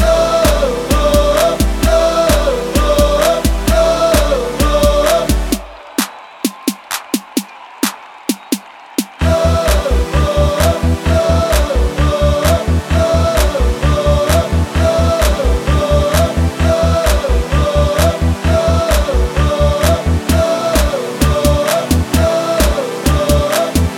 No Backing Vocals With Woahs R'n'B / Hip Hop 4:29 Buy £1.50